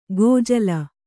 ♪ gō jala